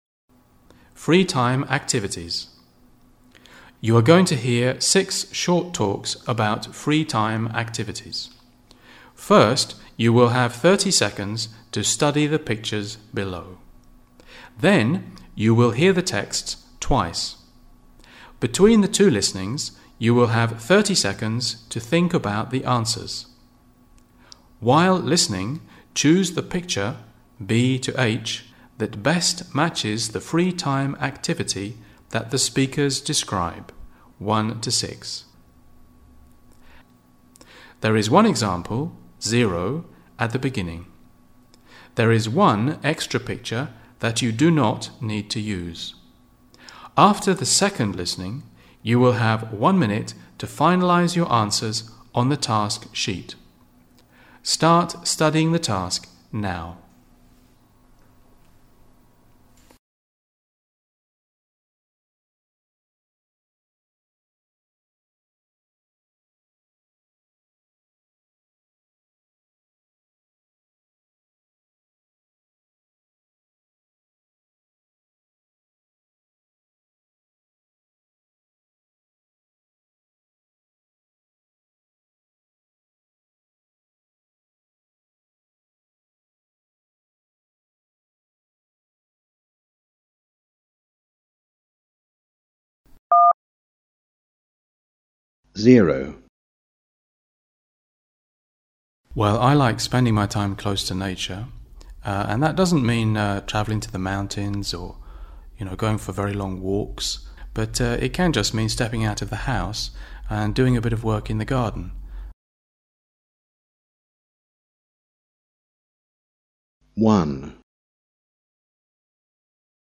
Beszédértés
You are going to hear 6 short texts.
Then you will hear the texts twice. Between the two listenings you will have 30 seconds to think about the answers. While listening, choose a picture (B-H) that best matches the free time activity that the speakers describe (1-6).
hobbies_task.mp3